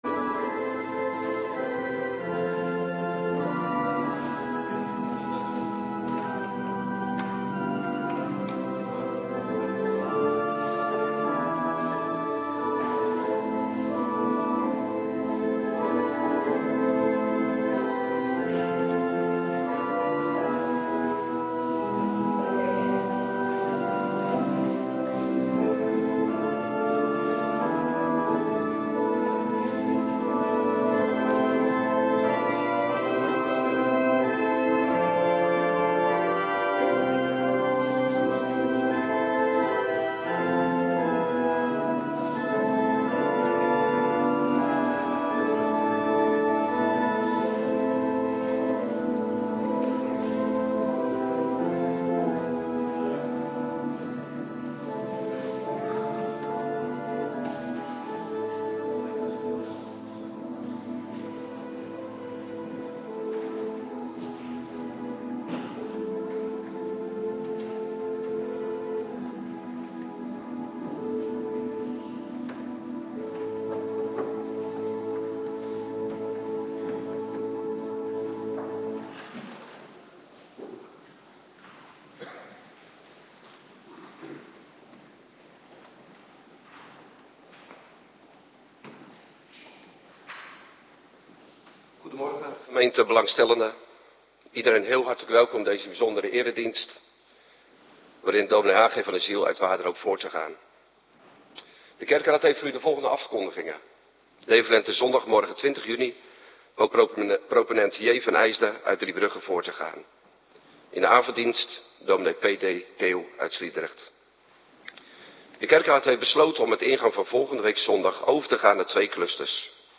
Morgendienst Heilig Avondmaal
Locatie: Hervormde Gemeente Waarder